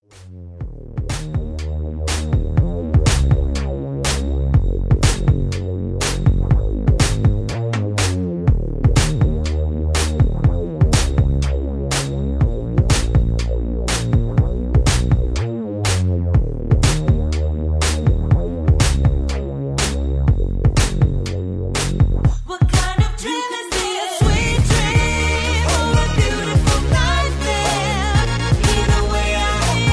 (Key-Gb) Karaoke Mp3 Backing Tracks